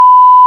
Beep+Censor